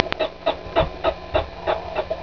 prairiedog.wav